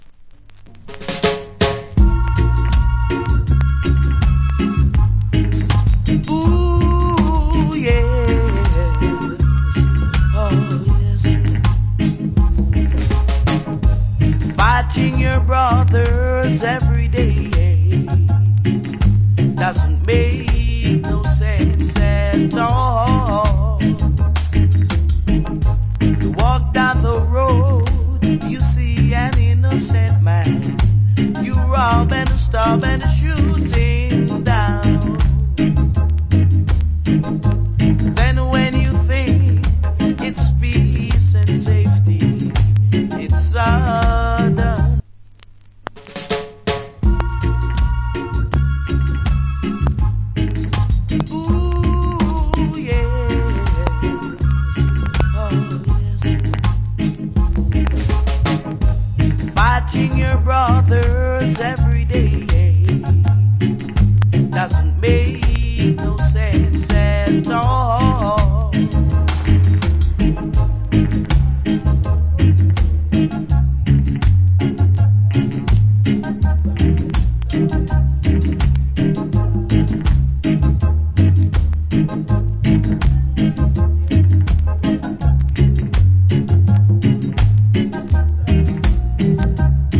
Nice Reggae Vocal.